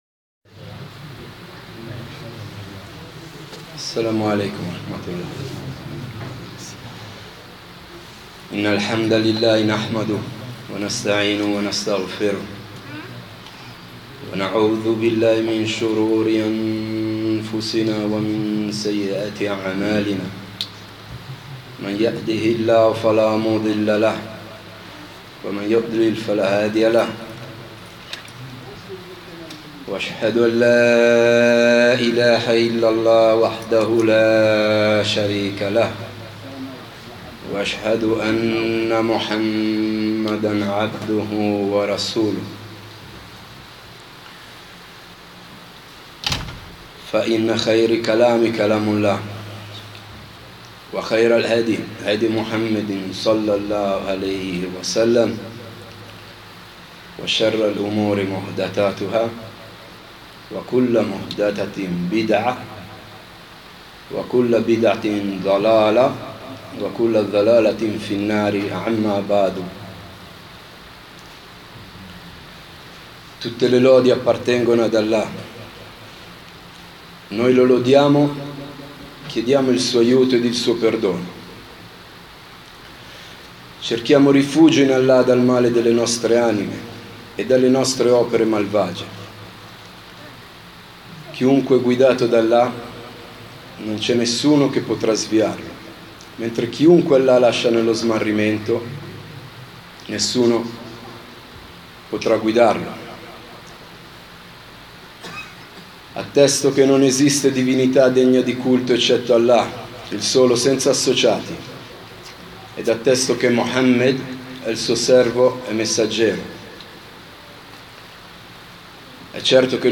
Lezioni